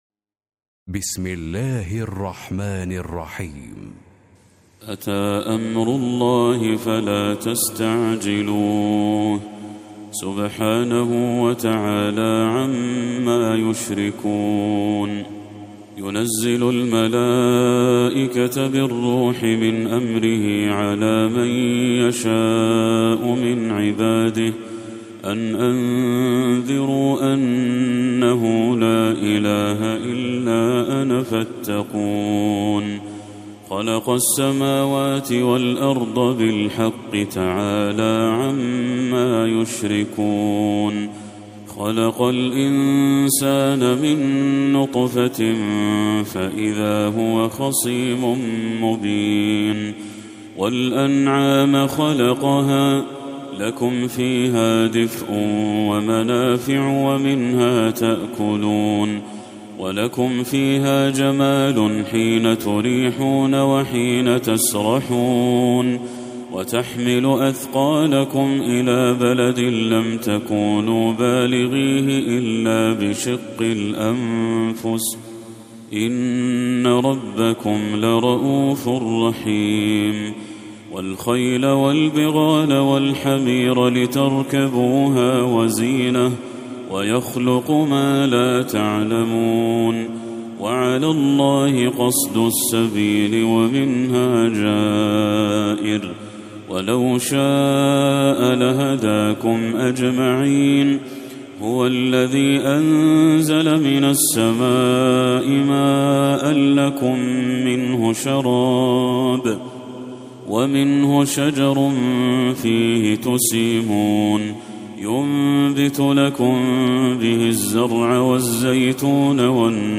سورة النحل Surat An-Nahl > المصحف المرتل